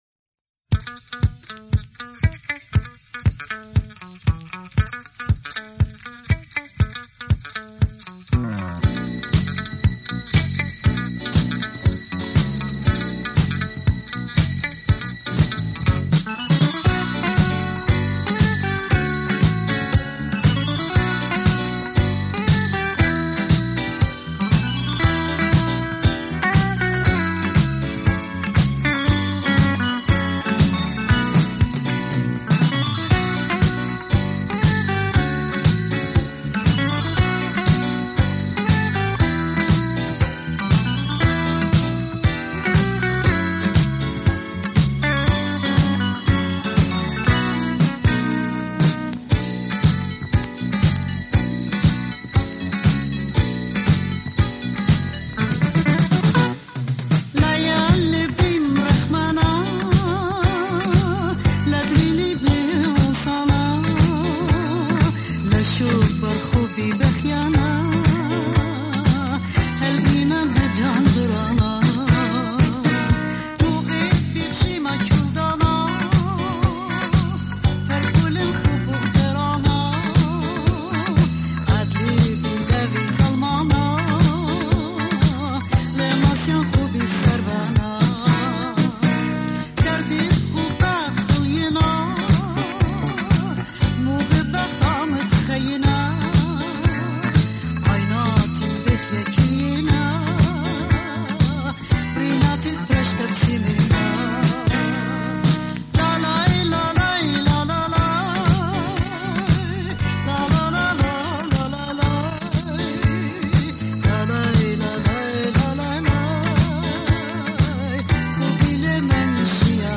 Keyboard & Guitar
Drums
Bass
Percussion